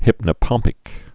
(hĭpnə-pŏmpĭk)